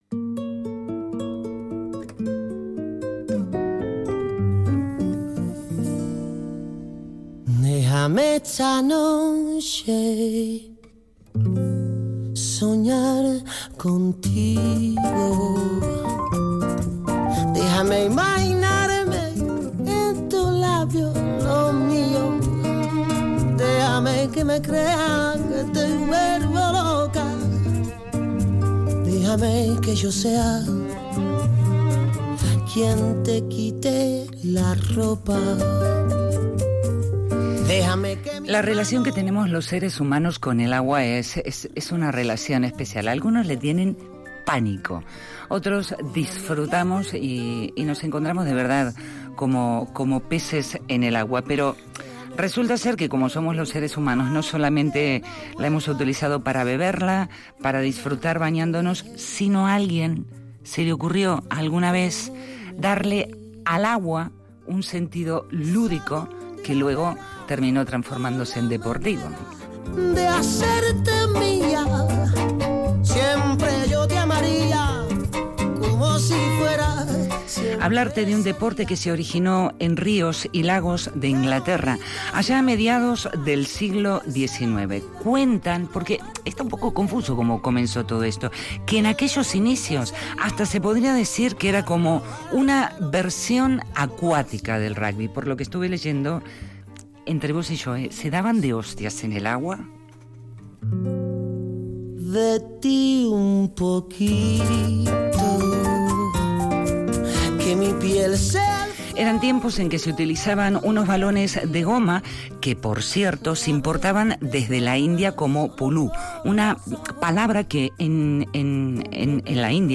Radio Vitoria LA FIACA